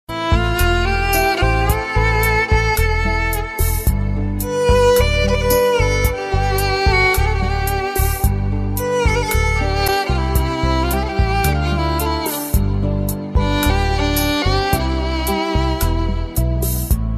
mp3 , dj mix ,